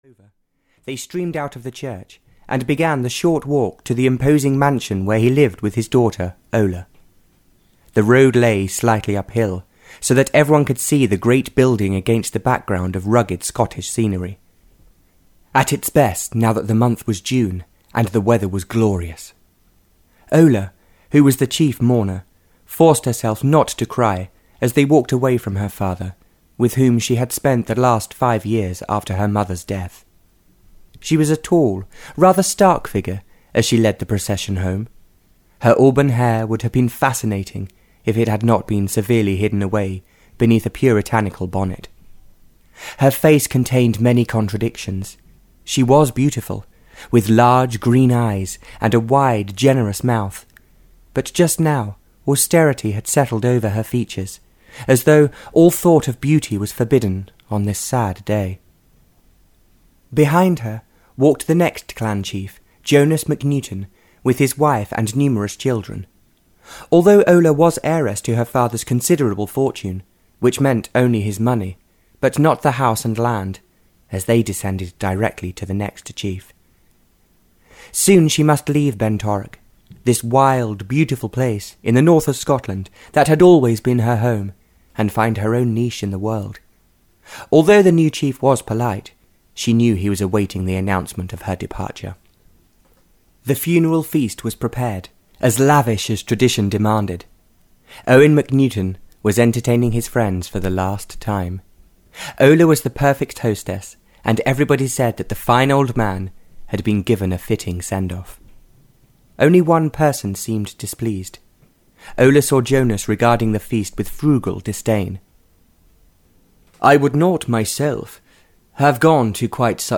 A Dangerous Disguise (Barbara Cartland’s Pink Collection 8) (EN) audiokniha
Ukázka z knihy